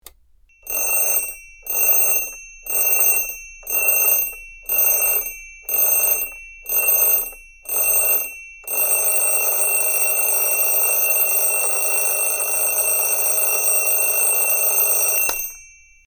Tải: Nhạc chuông Báo Thức Reng Reng Mp3